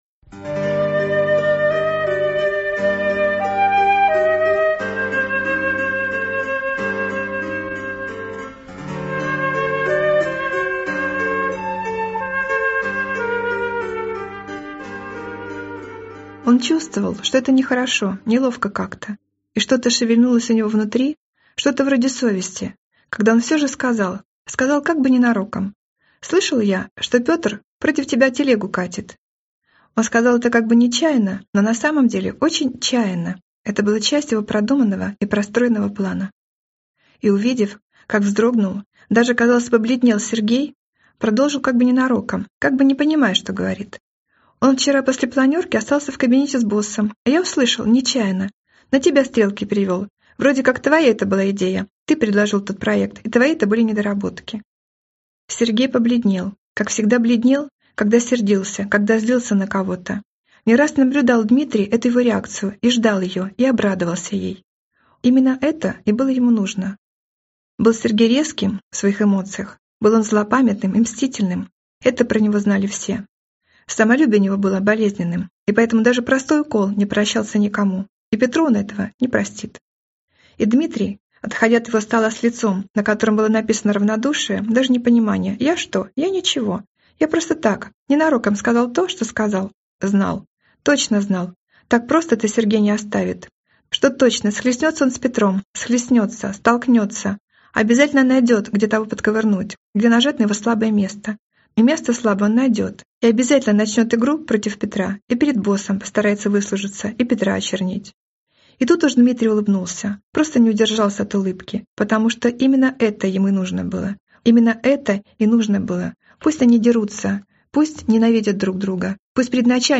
Аудиокнига Путь Бога | Библиотека аудиокниг